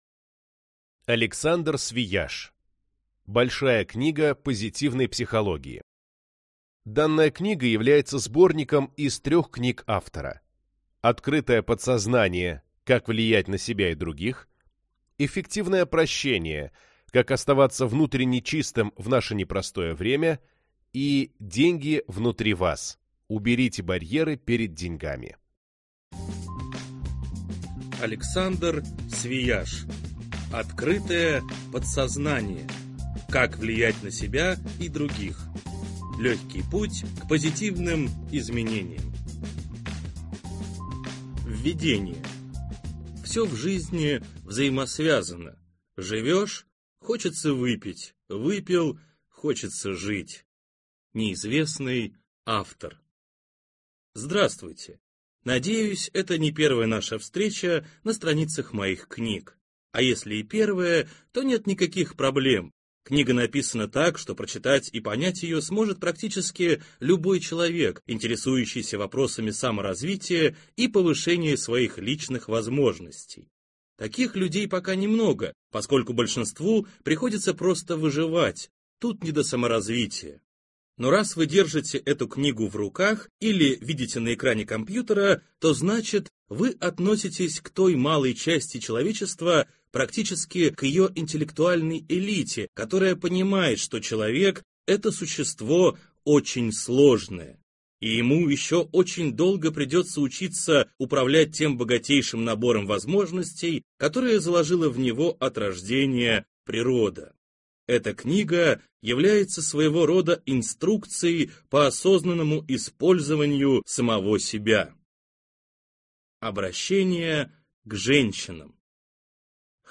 Аудиокнига Большая книга позитивной психологии | Библиотека аудиокниг